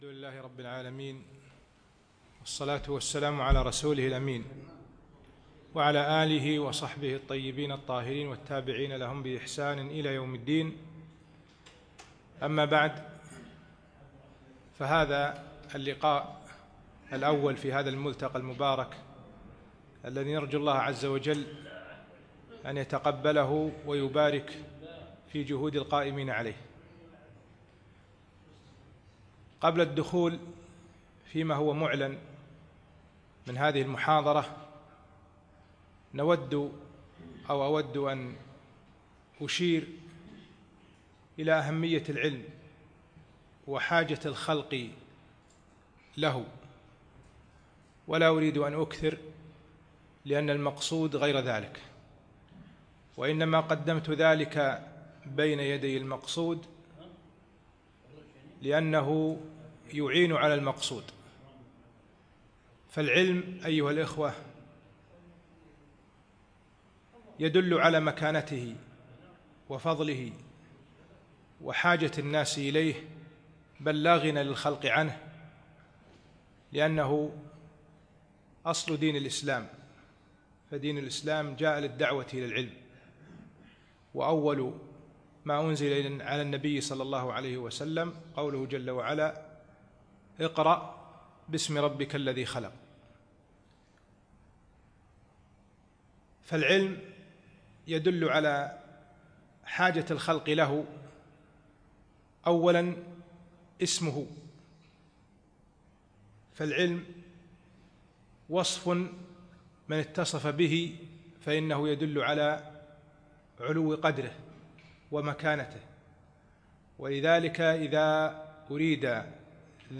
يوم الثلاثاء 8 جمادى الأخر 1438 الموافق 7 3 2017 في مسجد مضحي الكليب العارضية